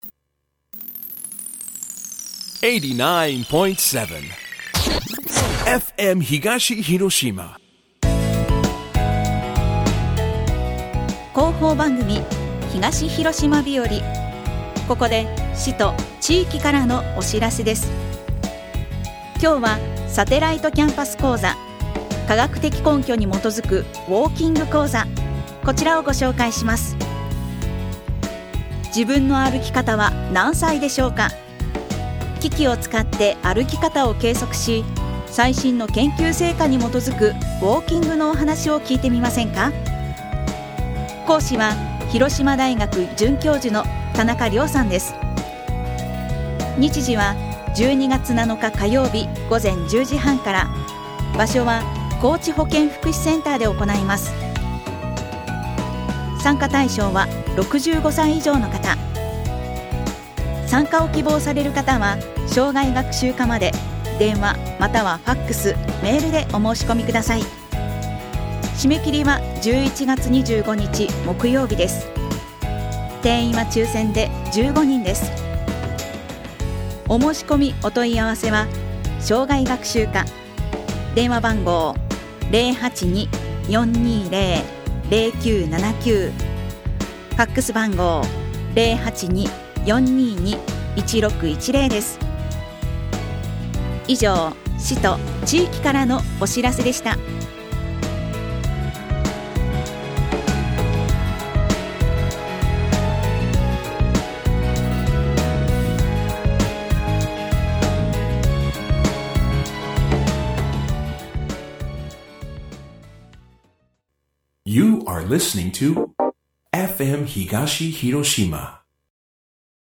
広報番組「東広島日和